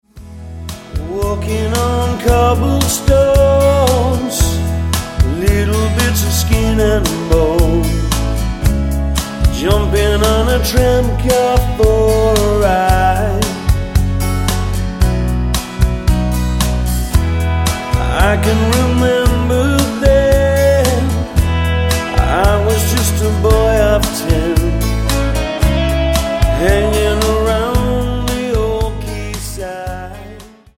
--> MP3 Demo abspielen...
Tonart:E Multifile (kein Sofortdownload.
Die besten Playbacks Instrumentals und Karaoke Versionen .